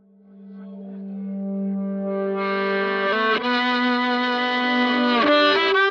violin reversed 001.wav